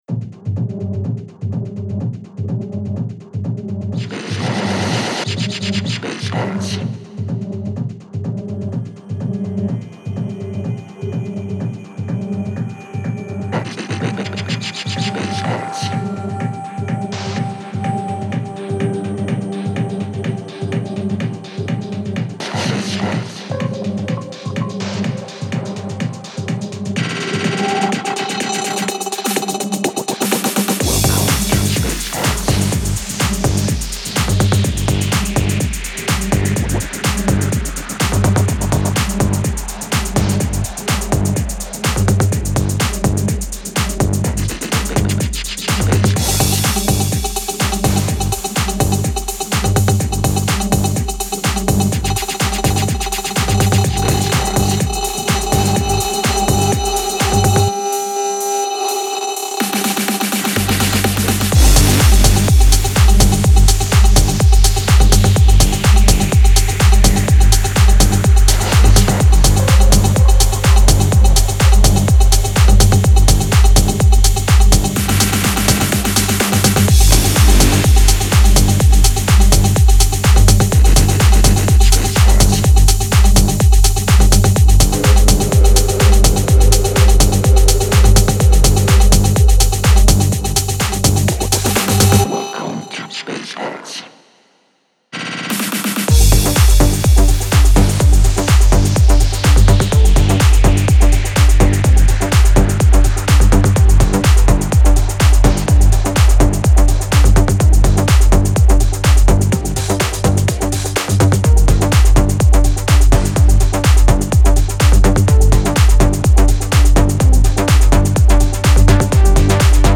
• Жанр: Музика, Techno